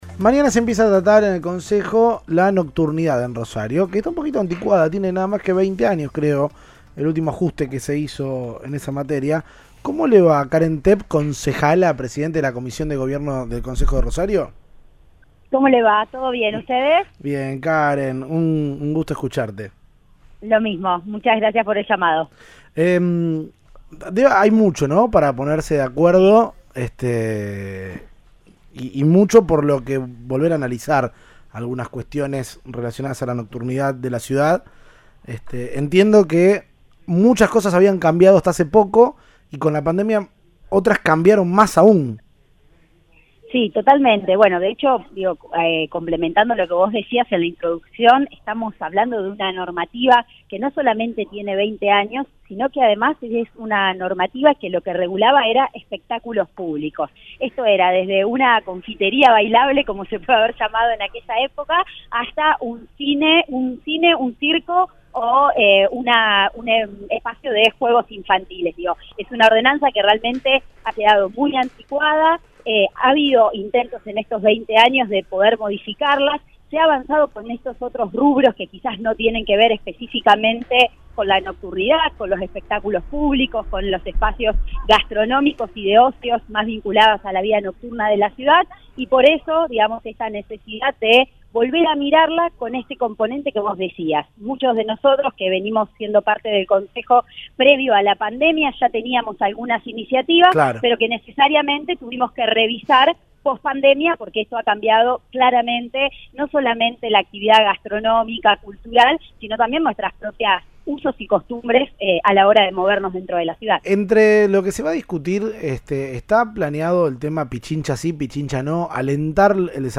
En el Concejo Municipal buscan nuevamente lograr una pronta sanción de cambios en la ordenanza que rige las propuestas nocturnas. La titular de la comisión de Gobierno habló en Cadena 3 Rosario.